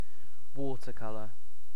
Ääntäminen
UK RP : IPA : /ˈwɔ.tɜ.ˌkʌ.lɜ/